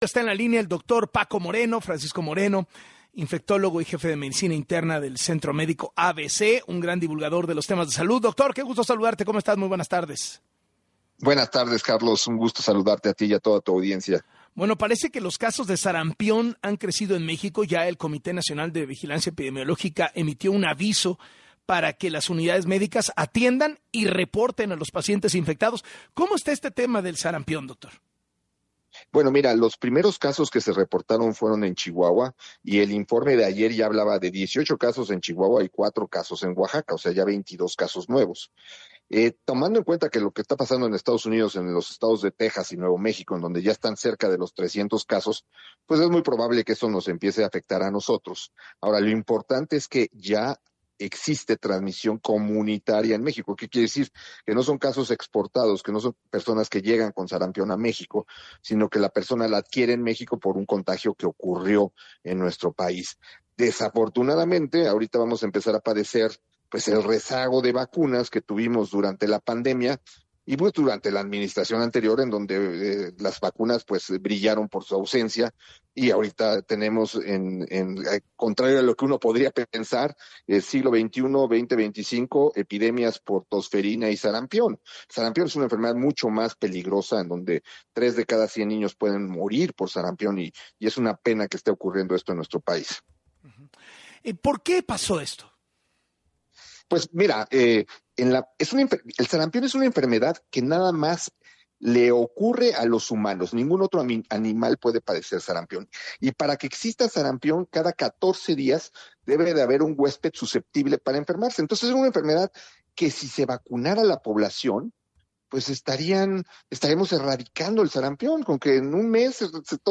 En entrevista con Carlos Loret de Mola, el infectólogo